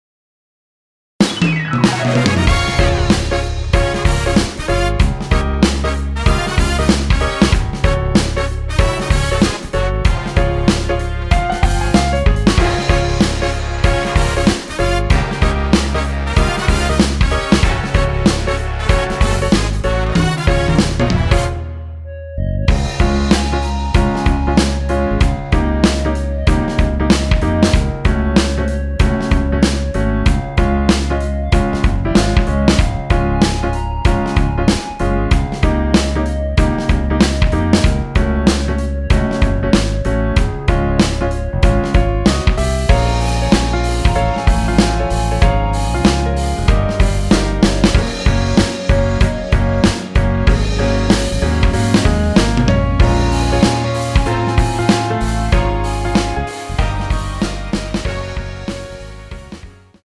원키에서(-1)내린 멜로디 포함된 MR입니다.(미리듣기 확인)
Db
앞부분30초, 뒷부분30초씩 편집해서 올려 드리고 있습니다.
중간에 음이 끈어지고 다시 나오는 이유는